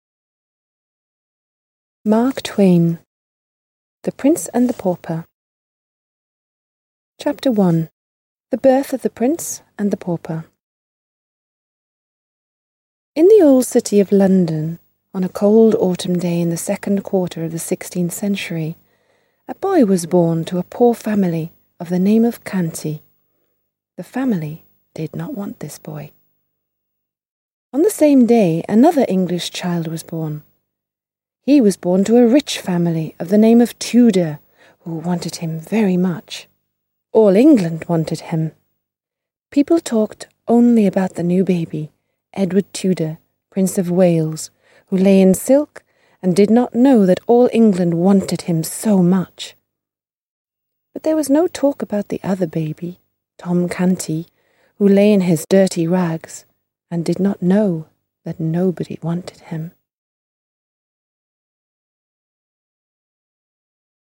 Аудиокнига The Prince and the Pauper | Библиотека аудиокниг